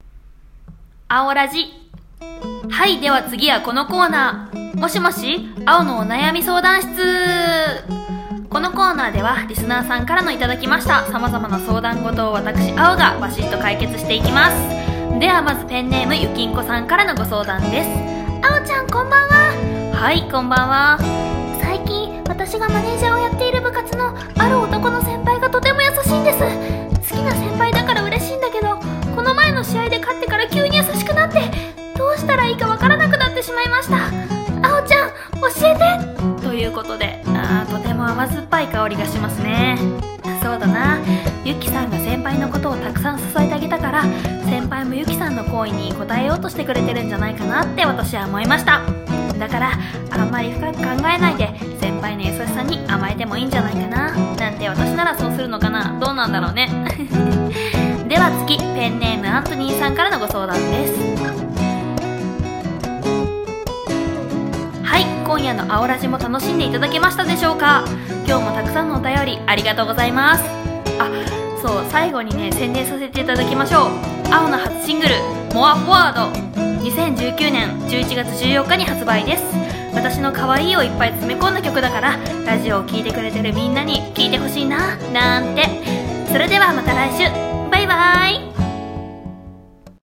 【声劇】ラジオ『あおラジ！』